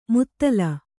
♪ muttala